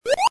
touchMushroom.mp3